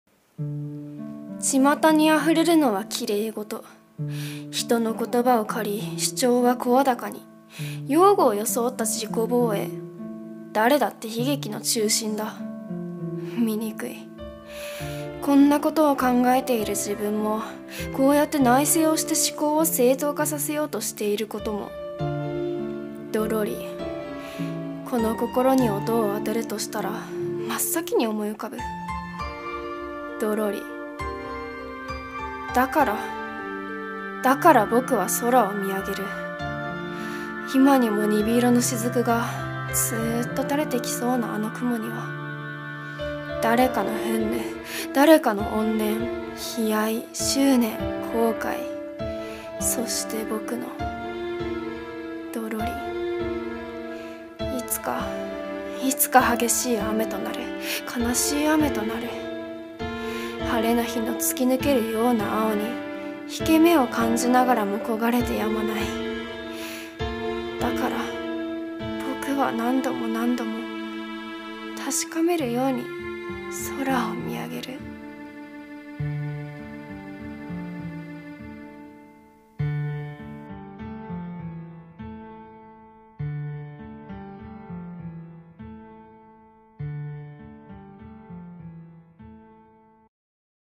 【一人声劇】夕立